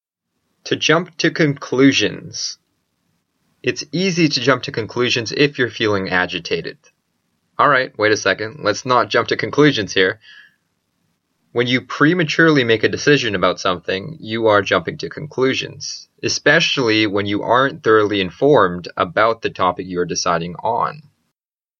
英語ネイティブによる発音は下記のリンクをクリックしてください。
tojumptoconclusions.mp3